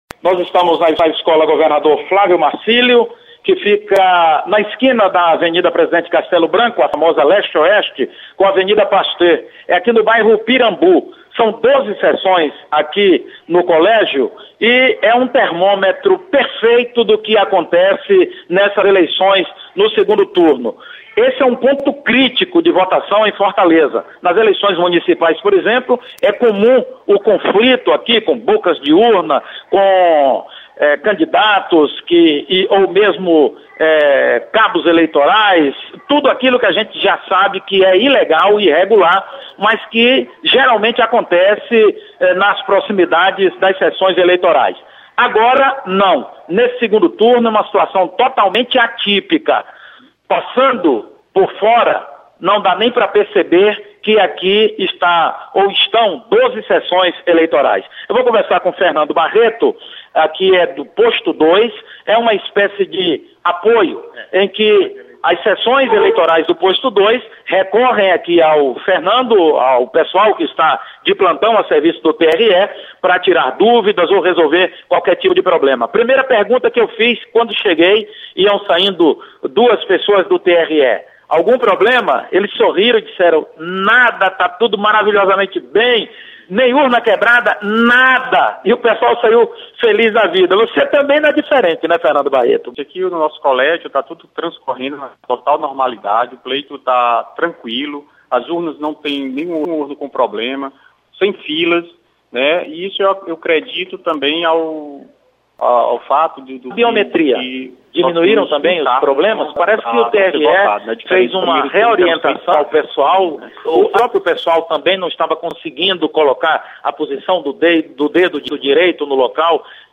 Votação segue tranquila na periferia de Fortaleza. Repórter